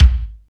26.03 KICK.wav